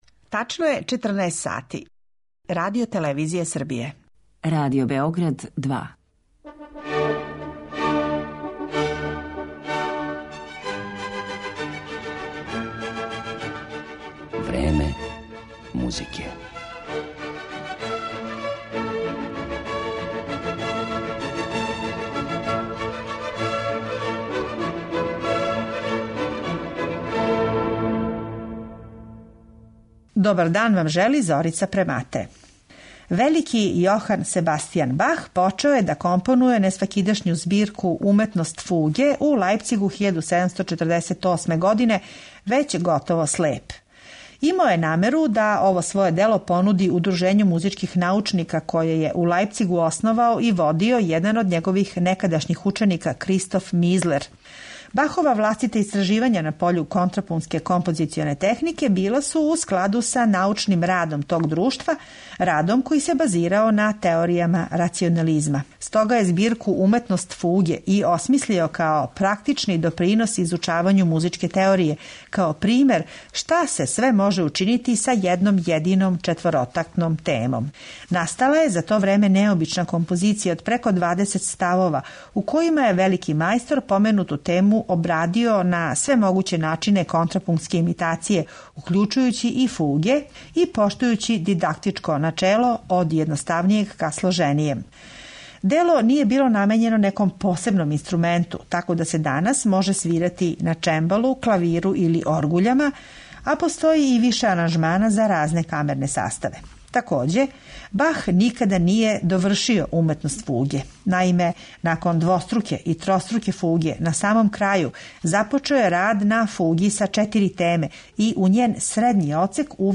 музичка дела, углавном полифоног стила и за оргуље